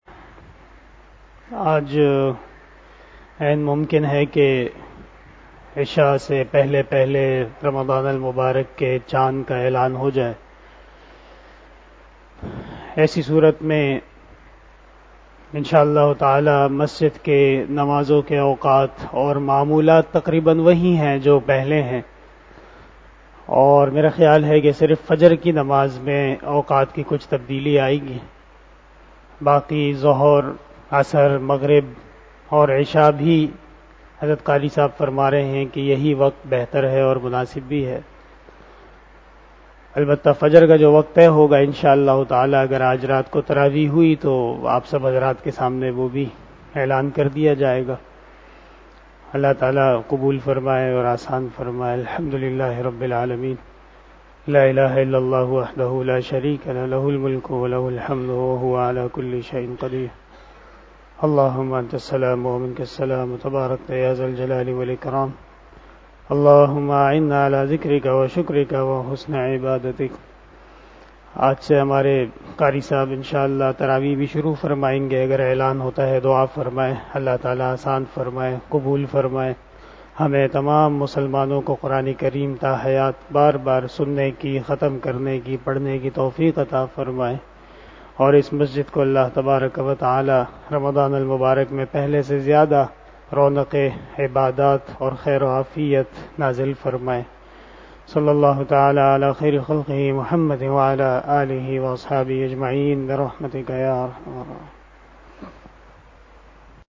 021 After Asar Namaz Bayan 02 April 2022 ( 29 Shaban 1443HJ) Saturday
بیان بعد نماز عصر